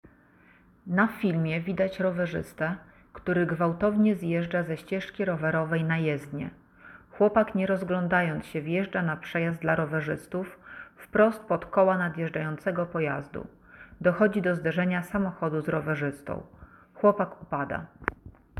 Autodeskrypcja do materiału zdarzenie drogowe z udziałem rowerzysty
Autodeskrypcjadomaterialuzdarzeniedrogowezudzialemrowerzysty.mp3